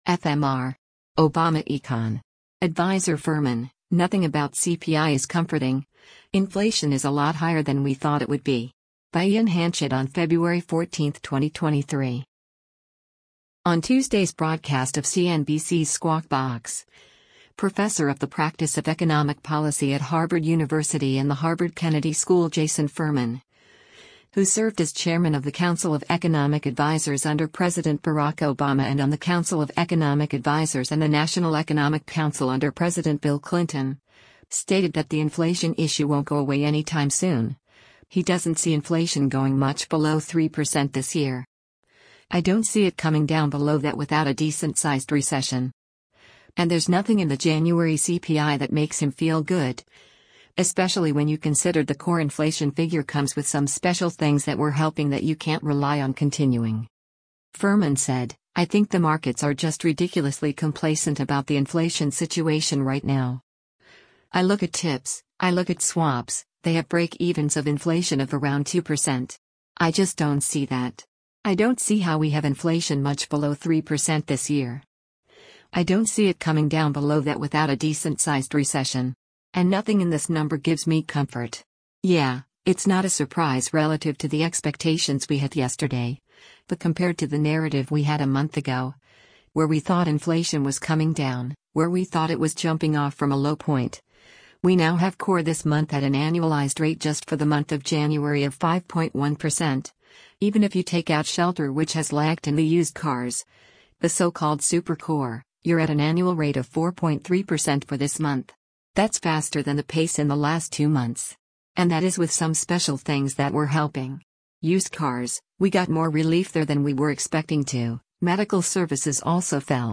On Tuesday’s broadcast of CNBC’s “Squawk Box,” Professor of the Practice of Economic Policy at Harvard University and the Harvard Kennedy School Jason Furman, who served as Chairman of the Council of Economic Advisers under President Barack Obama and on the Council of Economic Advisers and the National Economic Council under President Bill Clinton, stated that the inflation issue won’t go away anytime soon, he doesn’t see inflation going “much below 3% this year.